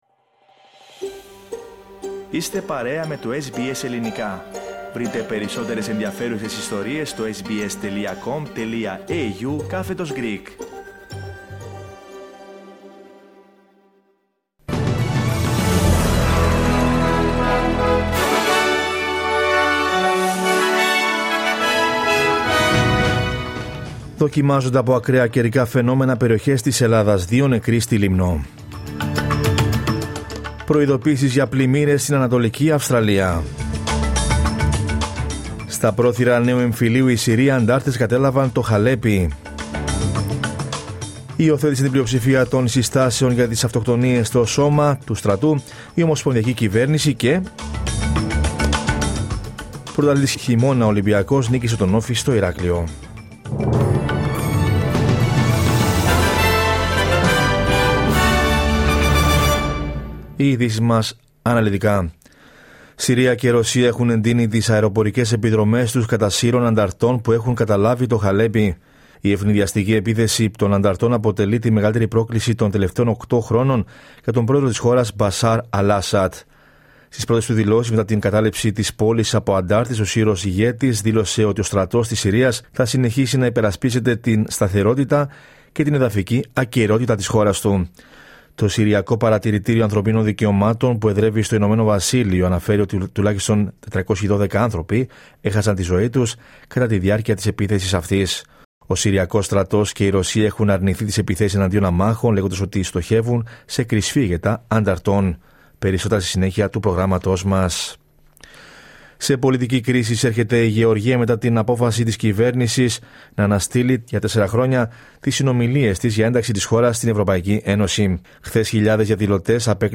Δελτίο Ειδήσεων Δευτέρα 2 Δεκεμβρίου 2024